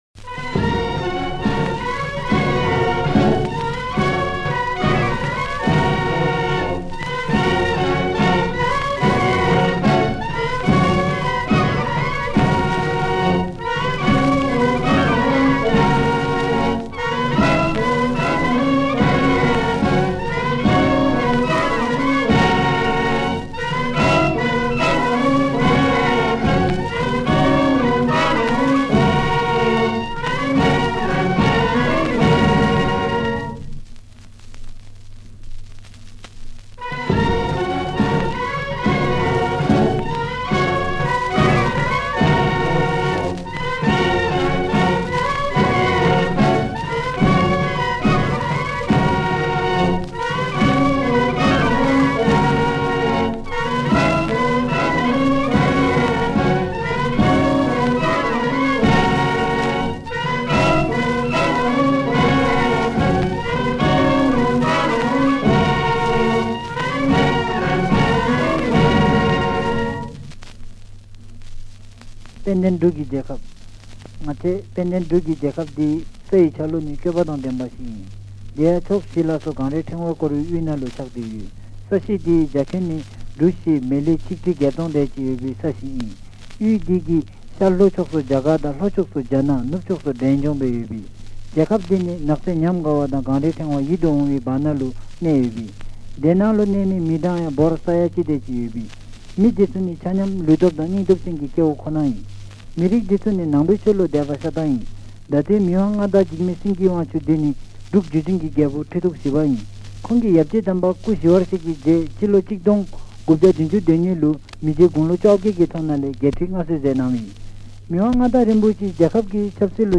These tiny records – possibly the smallest vinyl records that can still be played with a stylus – have an adhesive backside to affix to either a letter to postcard.